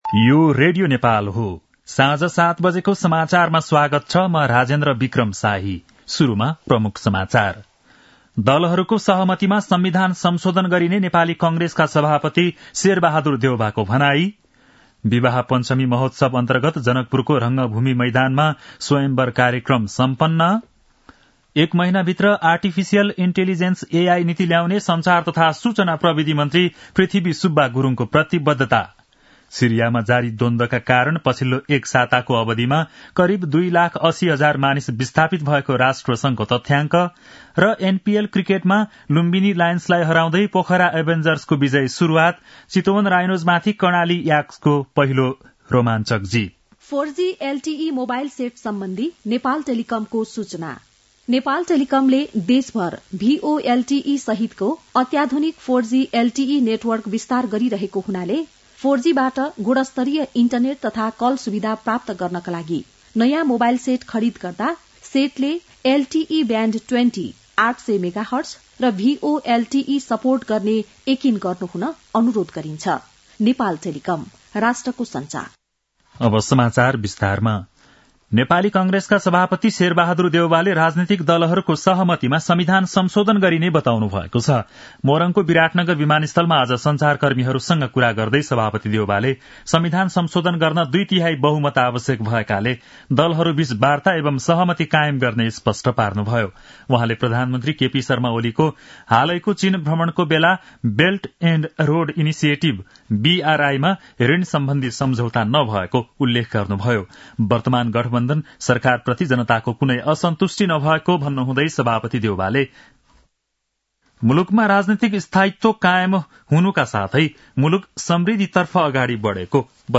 बेलुकी ७ बजेको नेपाली समाचार : २२ मंसिर , २०८१